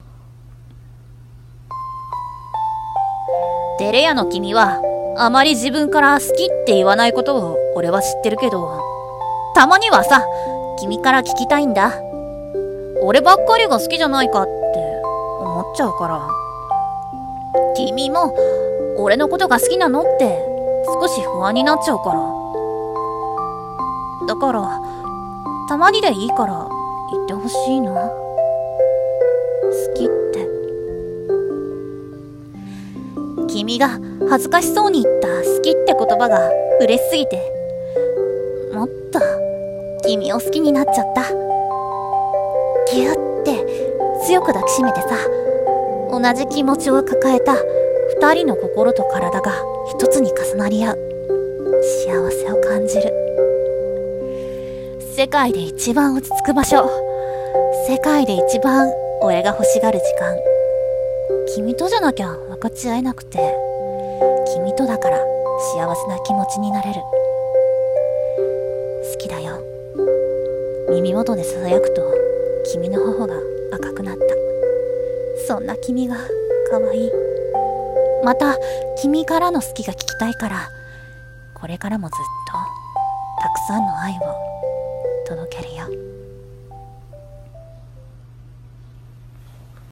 【言葉の魔法】※恋愛声劇